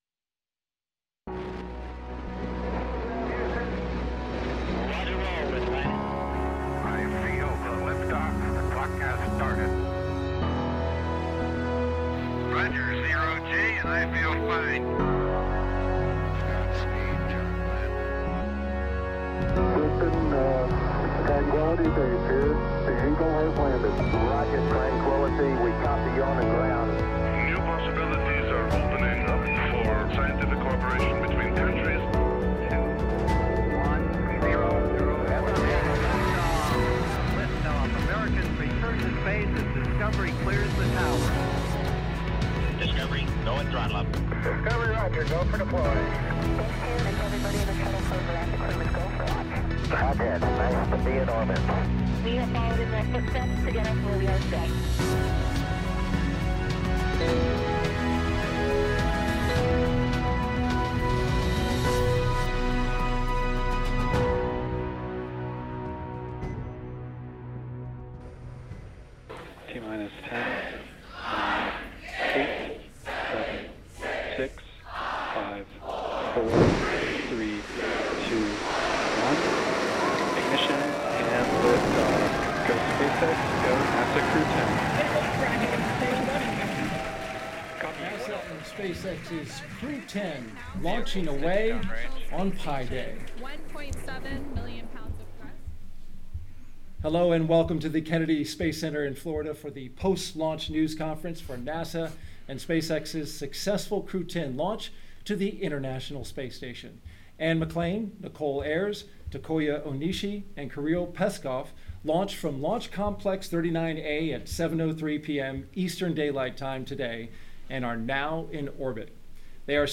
Members of the media attend a postlaunch news conference hosted at the agency’s Kennedy Space Center in Florida on Friday, March 14, 2025, following...